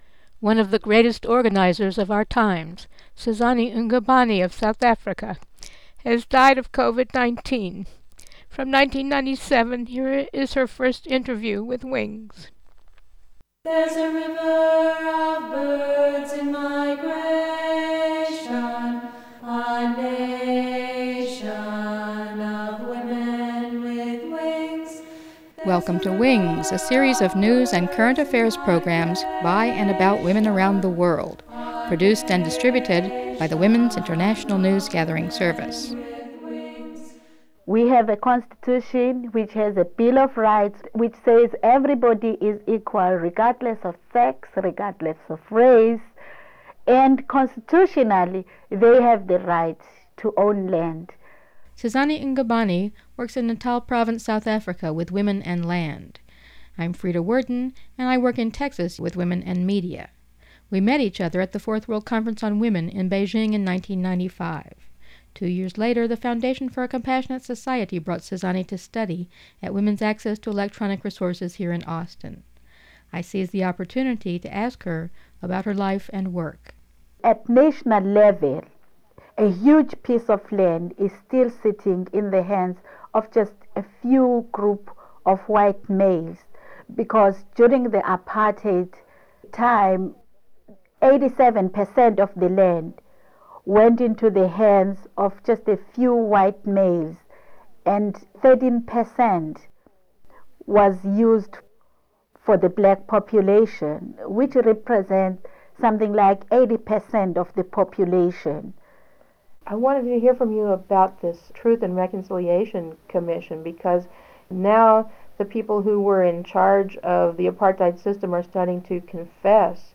Mono
Interview